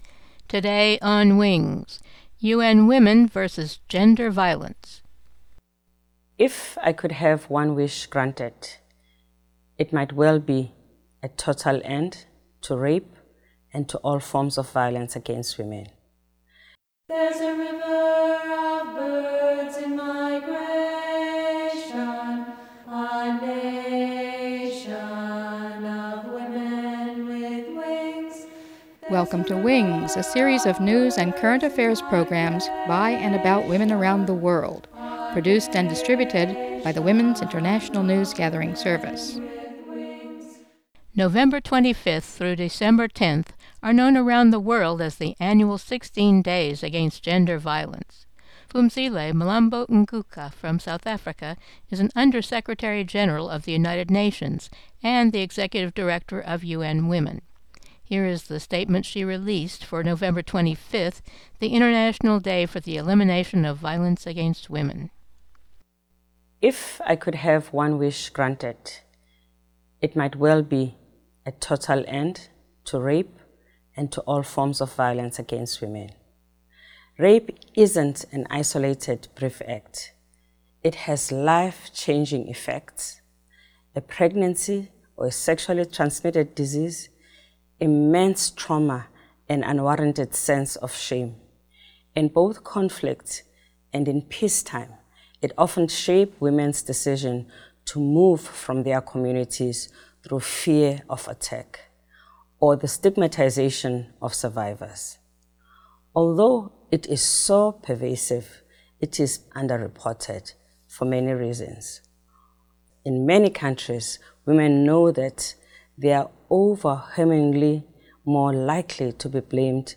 with a call to stop rape and an inter-generational feminist discussion